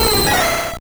Cri de Debugant dans Pokémon Or et Argent.